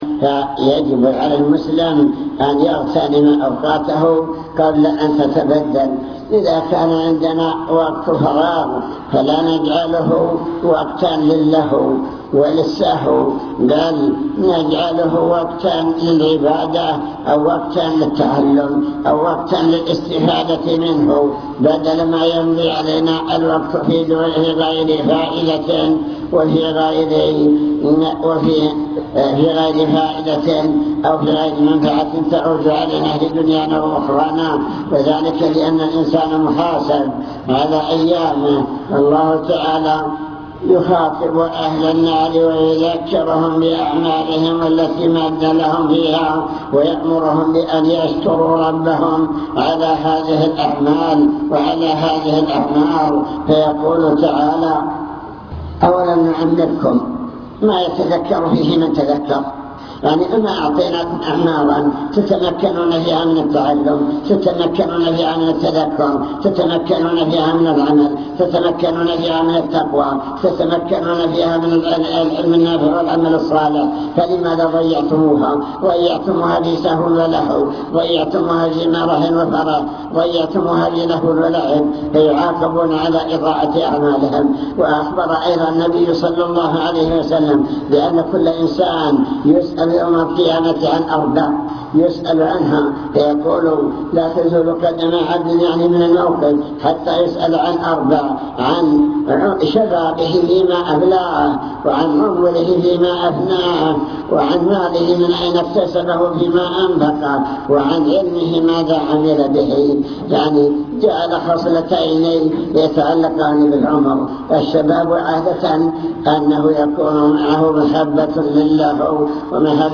المكتبة الصوتية  تسجيلات - محاضرات ودروس  محاضرة بعنوان شكر النعم (3) نماذج لنعم الله تعالى على العالمين